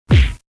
Title=coup_poing